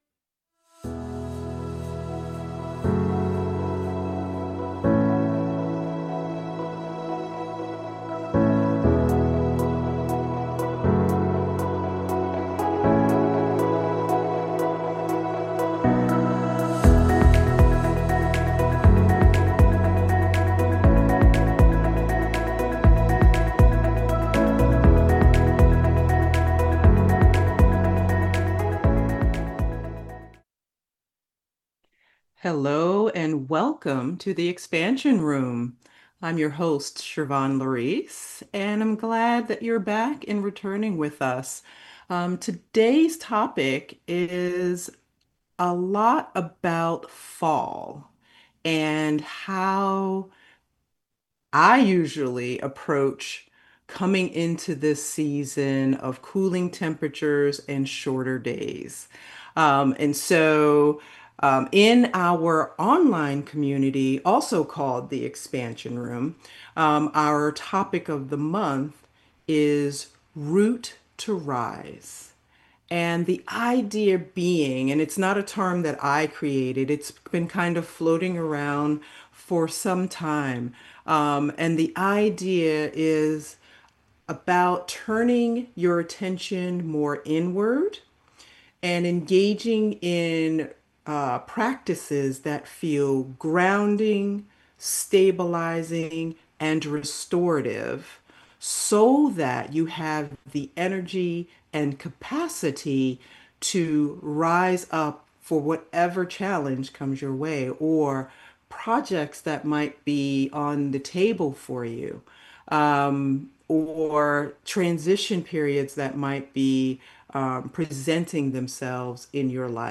Whether you are seeking more energetic stability in your life or are moving through a big life transition, this conversation will guide you to creating more harmony, strength and clarity.